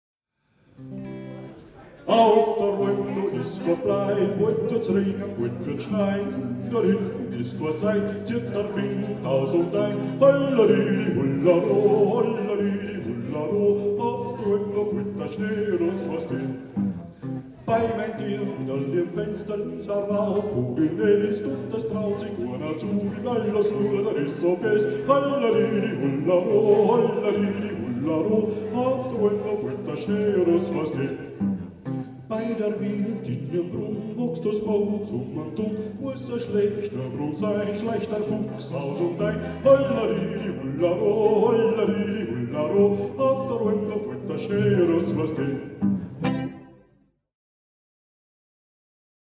Jodler, Jodler-Lied, Gstanzl und Tanz
1850–2019; the Lower Austrian and Styrian part of the Wechsel-region as well as the adjoining areas (Bucklige Welt, Lower Austria; Joglland, Styria)
Folk & traditional music